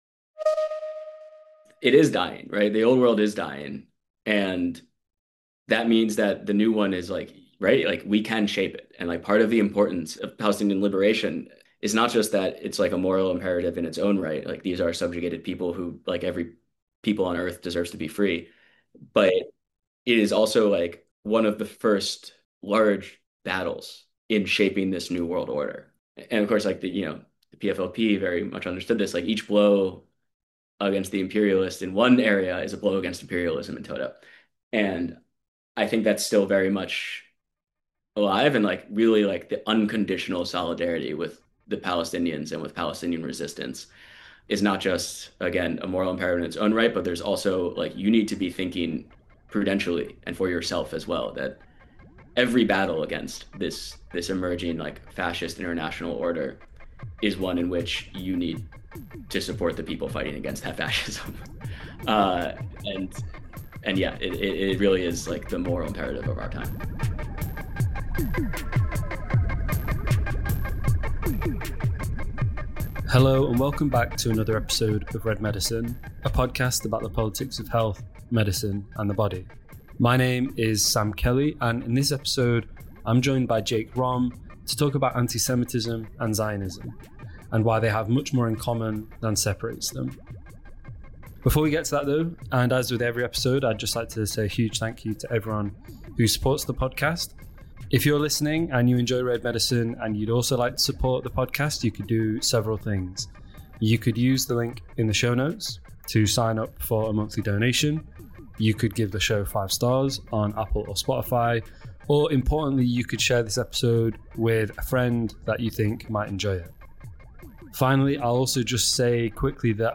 In this conversation we discuss the work of mid-century thinkers such as Jean Paul…